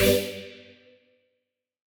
FR_T-PAD[hit]-G.wav